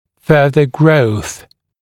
[‘fɜːðə grəuθ][‘фё:зэ гроус]дальнейший рост